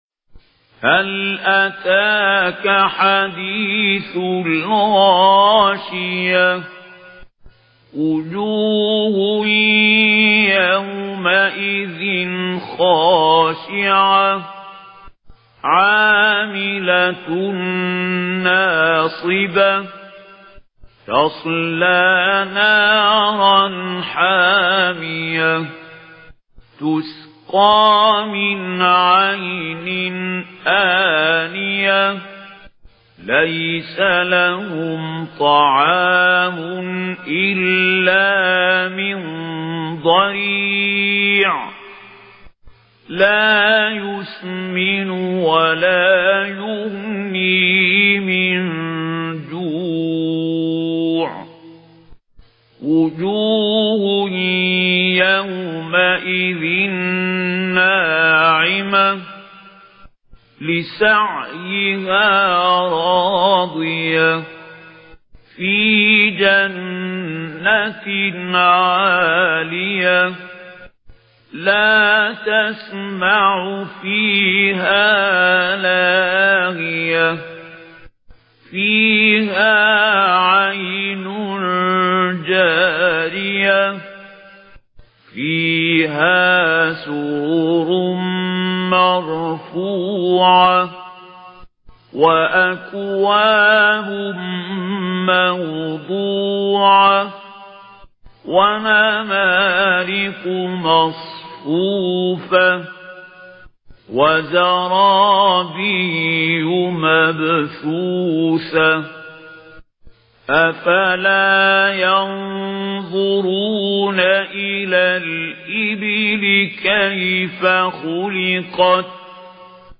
Récitation par Mahmoud Khalil Al Hussary